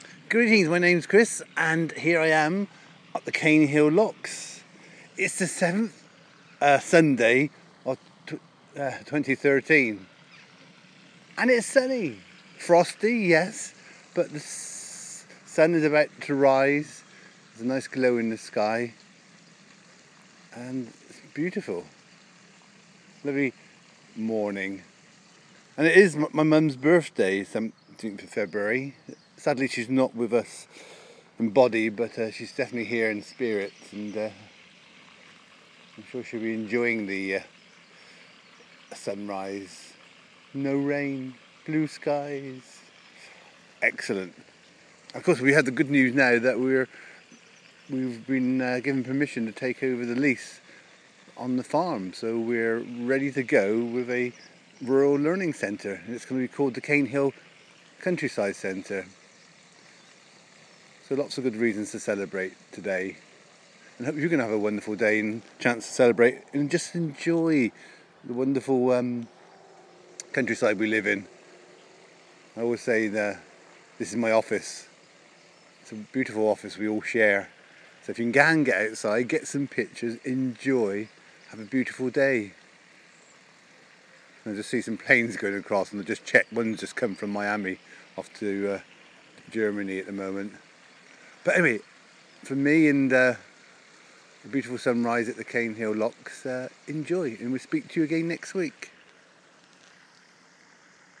7th Sunday of the year sunrise at the Caenhill locks